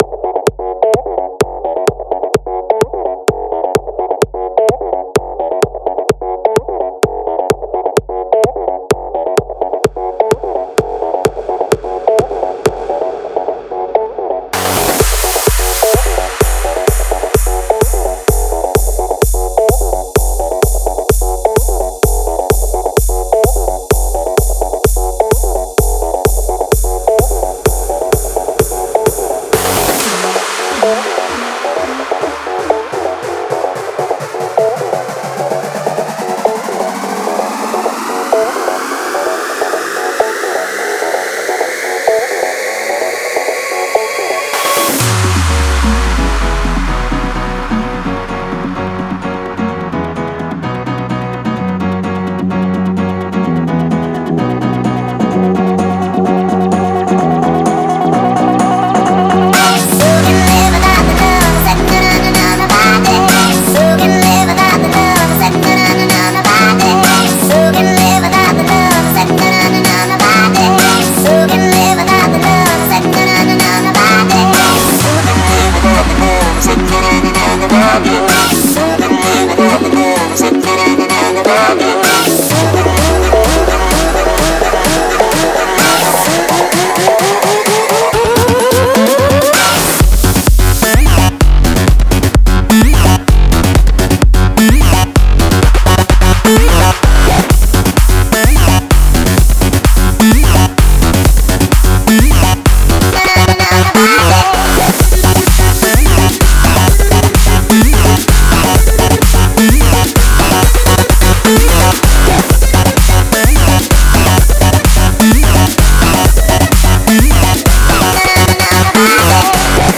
Плейлисти: Клубна музика